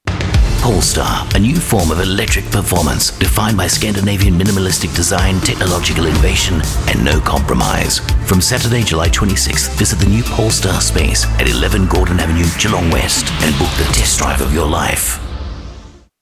Dynamic, approachable, friendly and natural Australian VoiceOver
Radio Commercials
Automotive-Sophisticated-Clear